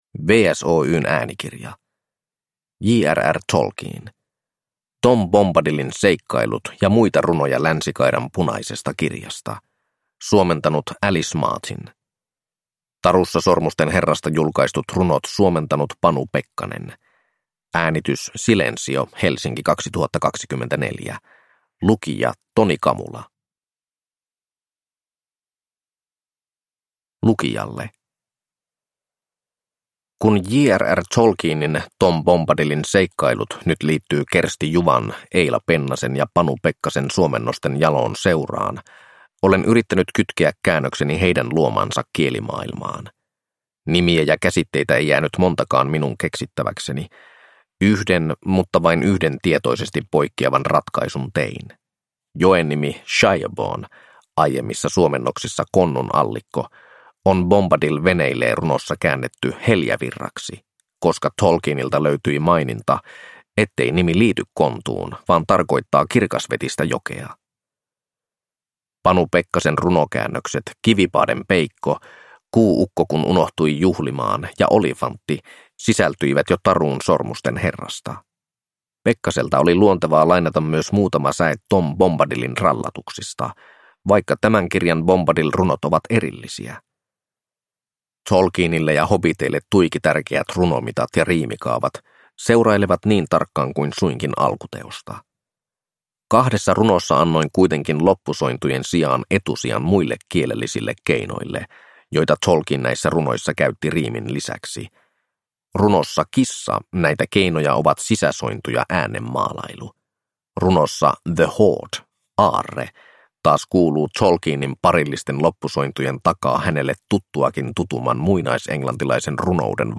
Tom Bombadilin seikkailut – Ljudbok